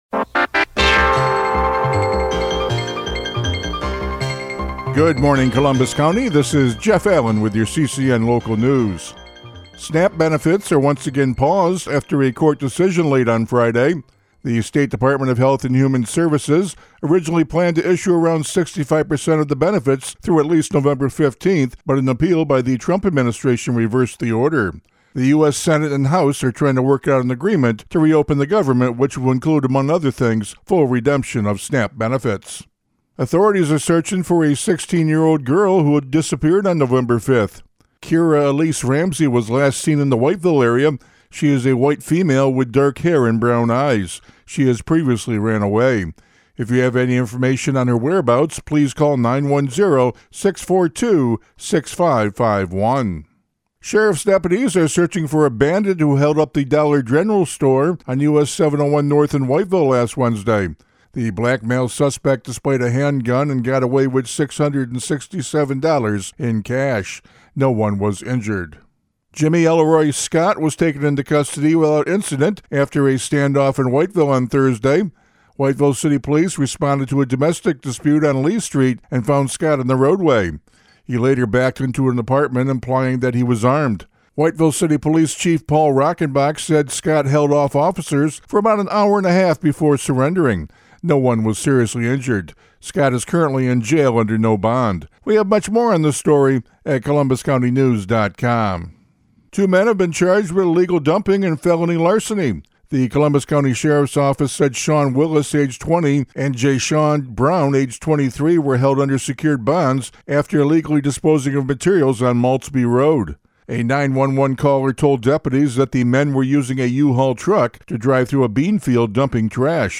CCN Radio News — Morning Report for November 10, 2025